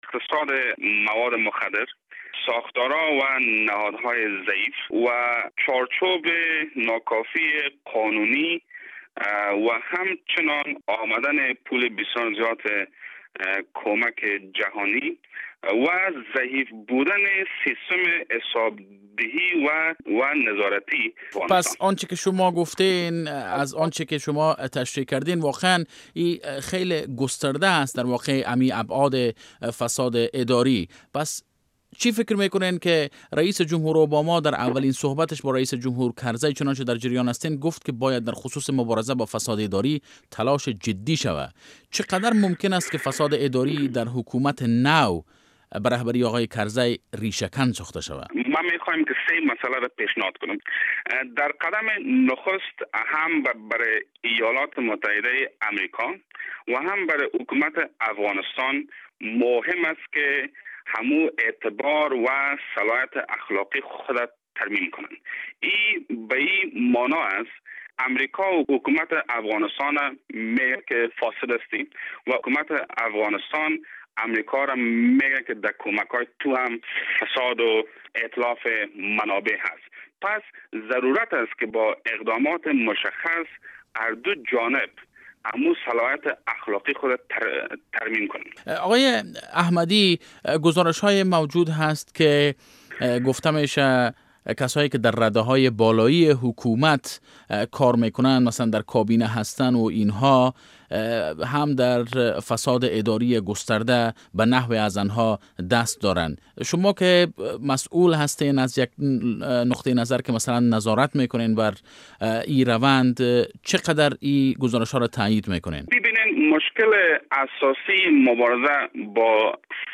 مصاحبه با ارشاد احمدی معاون کمیتهء مبارزه با فساد اداری